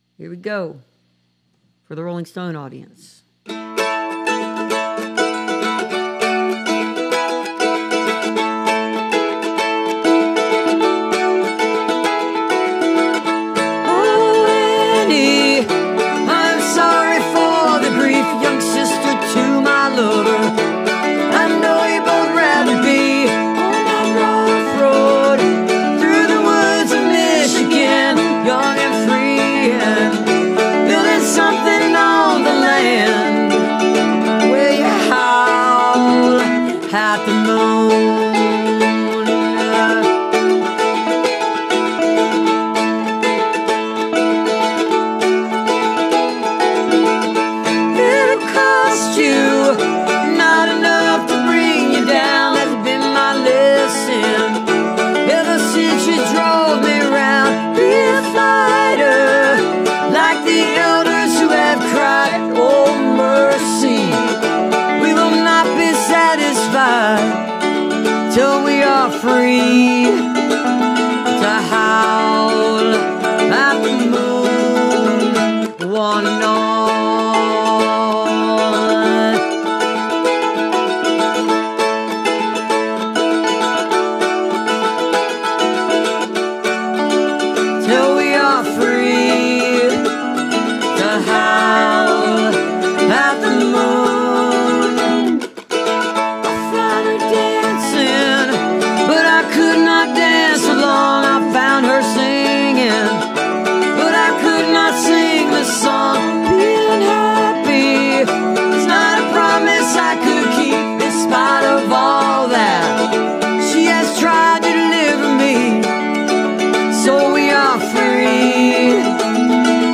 (captured from the video webcast)